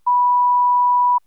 niohundraattiofem.wav